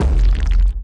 explosion_asteroid.wav